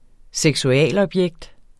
Udtale [ -ʌbˌjεgd ]